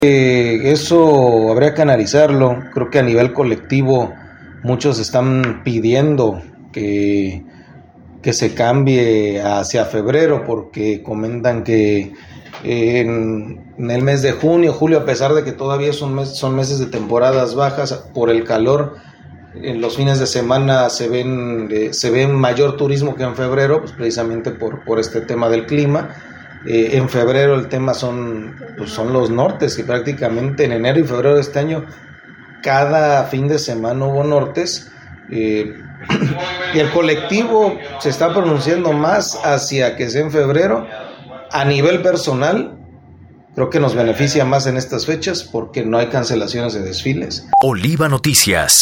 En entrevista, subrayó que se tenía previsto una ocupación del 70 por ciento; sin embargo, apenas lograron alcanzar el 60 por ciento, y gran parte de ello, consideró fue por las inclemencias del tiempo.